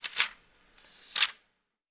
TURN PAGE7-S.WAV